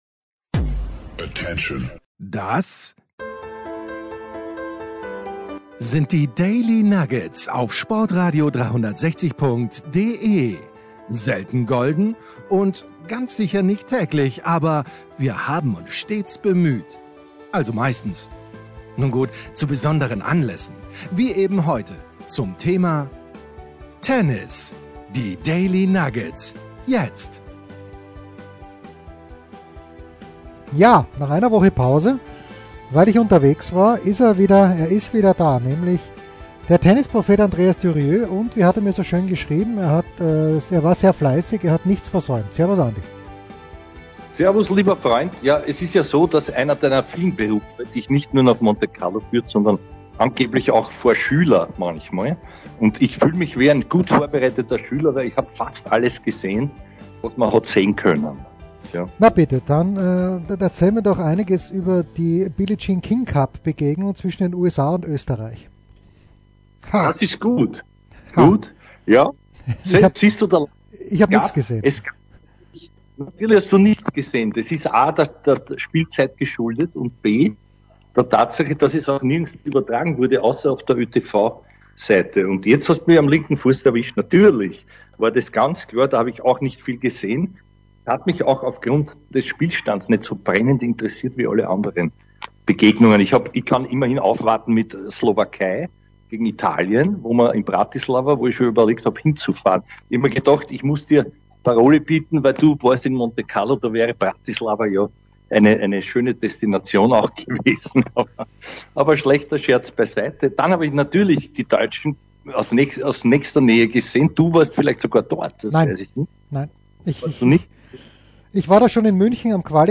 Und wir hören ein paar O-Töne von Dominic Thiem und Alexander Zverev.